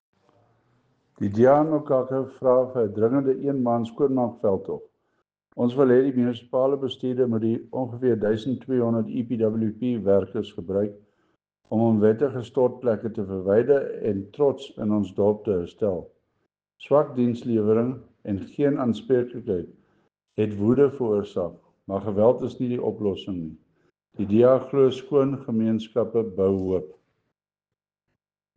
Afrikaans soundbites by Cllr Chris Dalton and